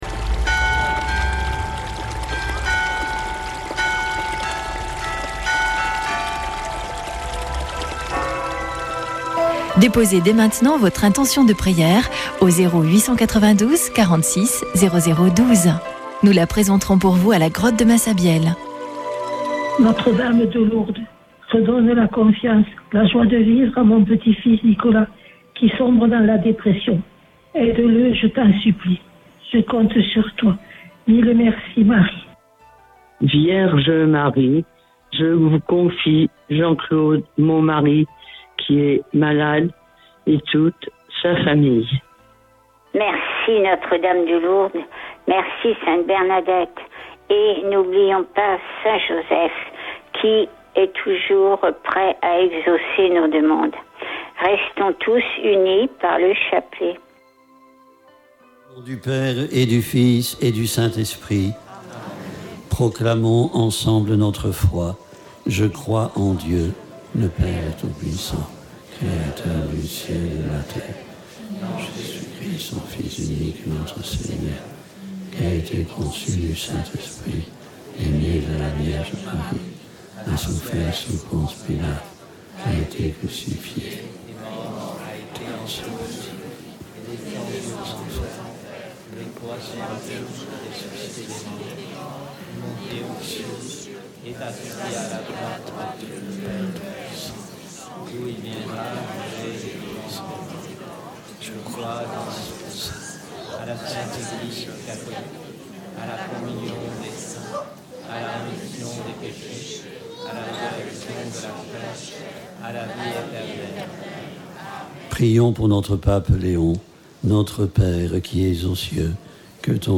Chapelet de Lourdes du 07 sept.